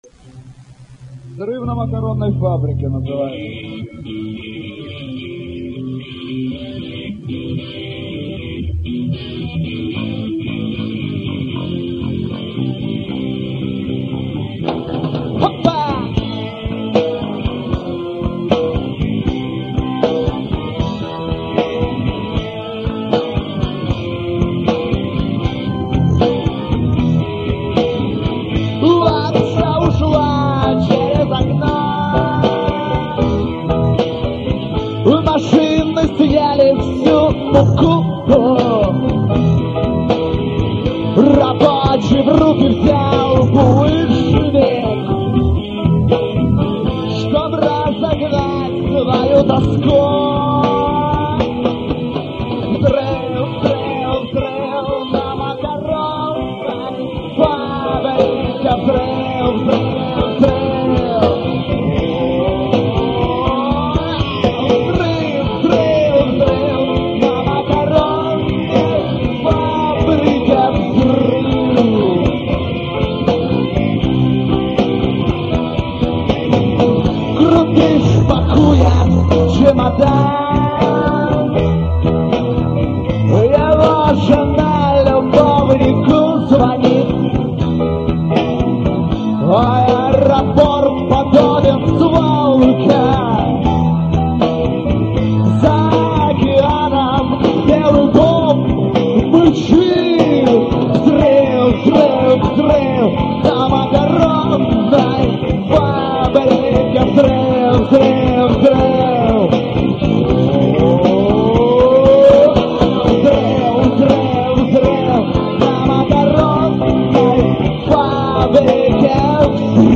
ДК Металлугров 2-е октября 99г.